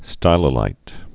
(stīlə-līt)